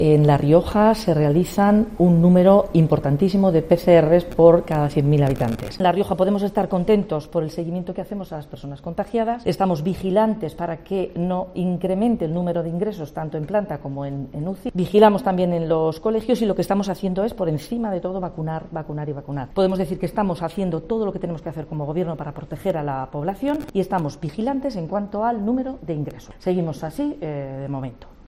La presidenta del Gobierno de La Rioja, Concha Andreu, explica que "la realización de muchas pruebas PCR en la comunidad ha permitido detectar más casos positivos de COVID-19", lo que ha elevado la incidencia acumulada en la última semana.